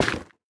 Minecraft Version Minecraft Version 25w18a Latest Release | Latest Snapshot 25w18a / assets / minecraft / sounds / block / nylium / break6.ogg Compare With Compare With Latest Release | Latest Snapshot
break6.ogg